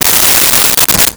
Silverware Movement 01
Silverware Movement 01.wav